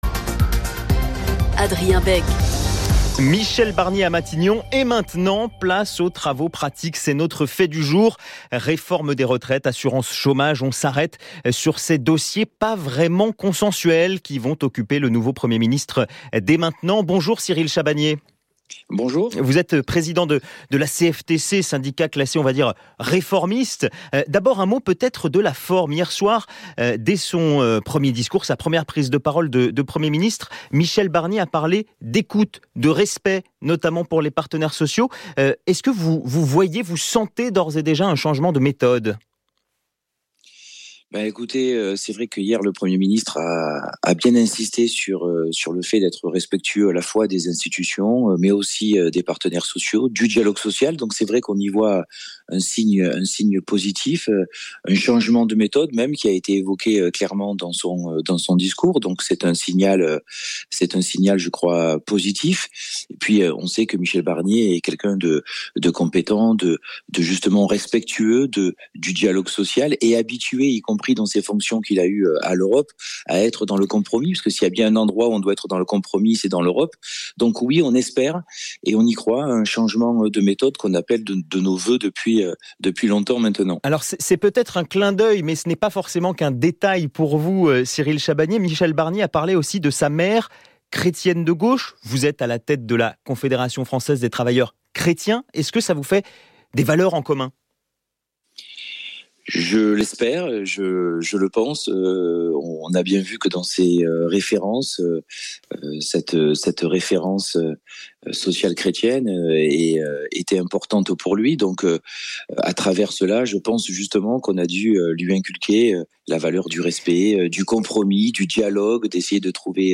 Retrouvez ici des extraits de son intervention: